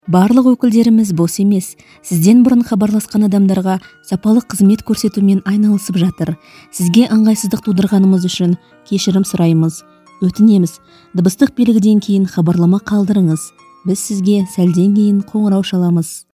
女哈萨克102T 哈萨克语女声 轻柔的2 低沉|激情激昂|大气浑厚磁性|沉稳|娓娓道来|科技感|积极向上|时尚活力|神秘性感|调性走心|亲切甜美|感人煽情|素人 00:00 01:28 点击下载 点击收藏 女哈萨克102T 哈萨克语女声 轻柔的 1 低沉|激情激昂|大气浑厚磁性|沉稳|娓娓道来|科技感|积极向上|时尚活力|神秘性感|调性走心|亲切甜美|感人煽情|素人 00:00 01:28 点击下载 点击收藏